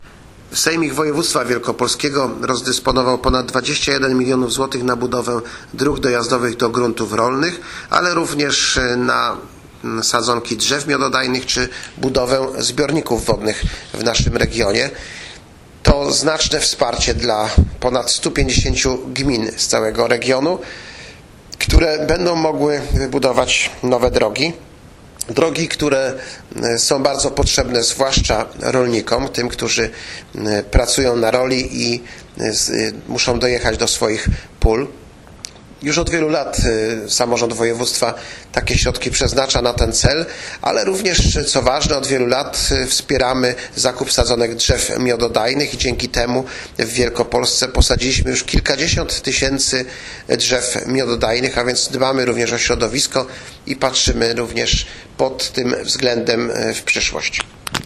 Wypowiedz-Wicemarszalka-K.-Grabowskiego-dotyczaca-wsparcia-na-ochrone-gruntow-rolnych-w-Wielkopolsce-2025.mp3